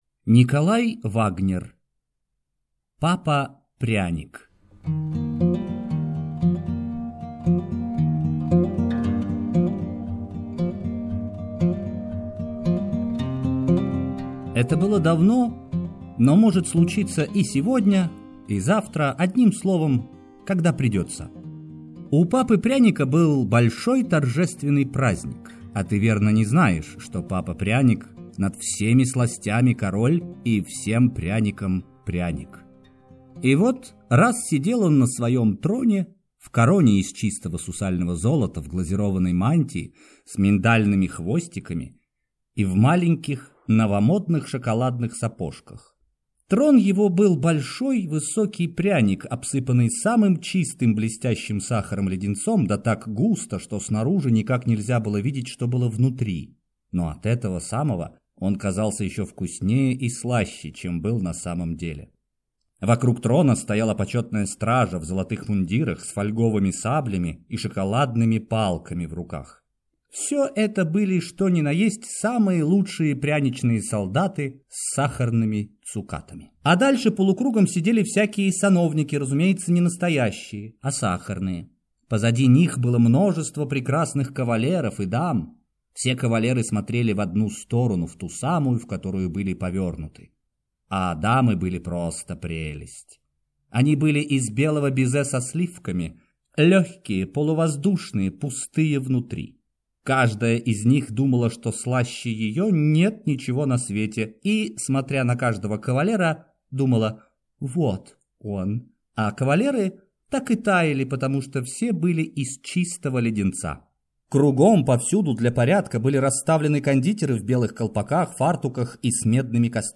Аудиокнига Папа-пряник | Библиотека аудиокниг